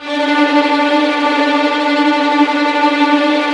Index of /90_sSampleCDs/Roland LCDP13 String Sections/STR_Violins Trem/STR_Vls Trem wh%